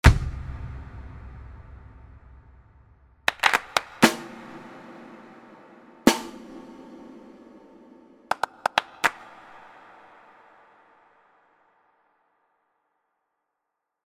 Um die Dichte, Transparenz und Auflösung der Raumsimulationen zu beurteilen, habe ich kurze Impulse, nämlich eine Bassdrum, eine Snare und Claps verwendet.
Hier fällt auf, dass die Bassdrum auch einen „bauchigen“ Hall erzeugt, die Claps in den Höhen schillern – sehr schön.